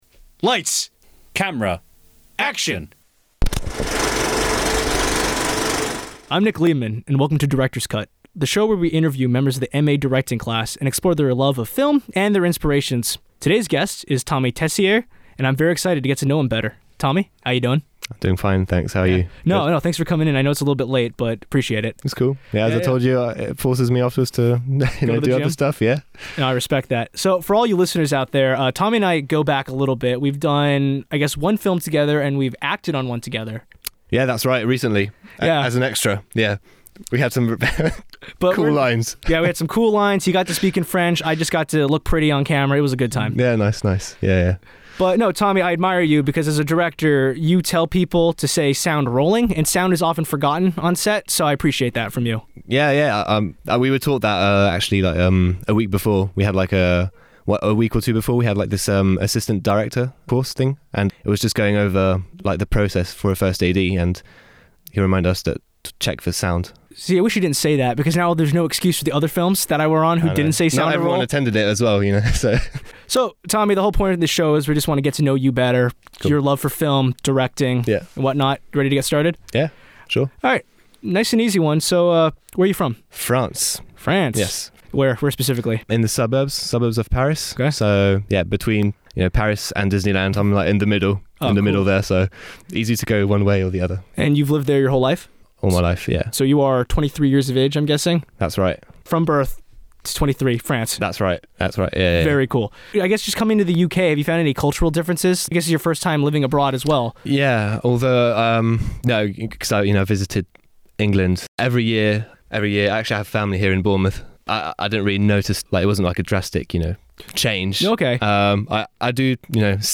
A causal conversation podcast with MA Directors to learn about their inspirations, and passion for film.